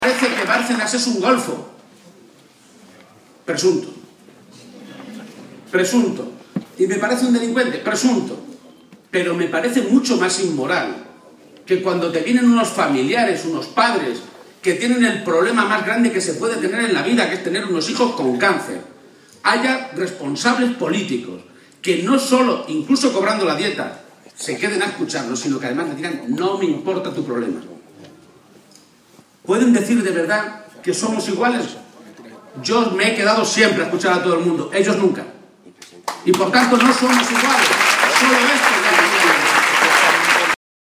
“No hay que caer en la trampa, hay que parar la coartada del Partido Popular”, insistió, en transcurso del mitin de cierre de campaña que esta noche se celebró en Albacete.